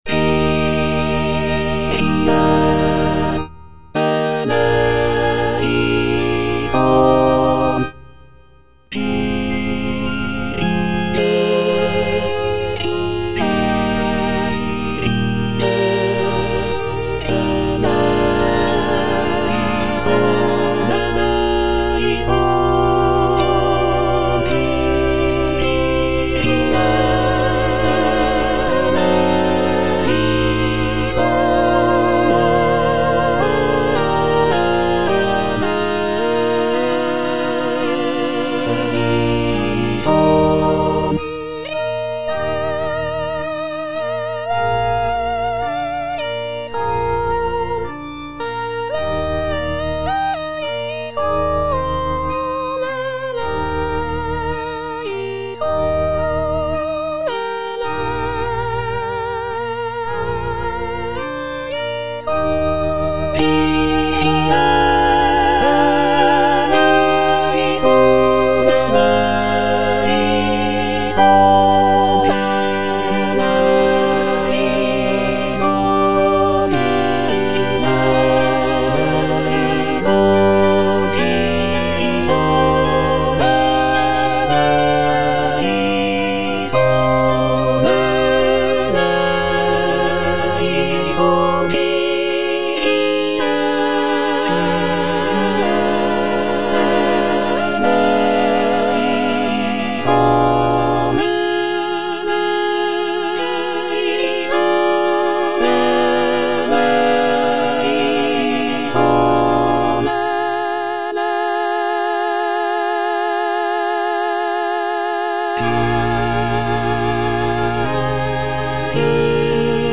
C.M. VON WEBER - MISSA SANCTA NO.1 Kyrie eleison (All voices) Ads stop: auto-stop
"Missa sancta no. 1" by Carl Maria von Weber is a sacred choral work composed in 1818.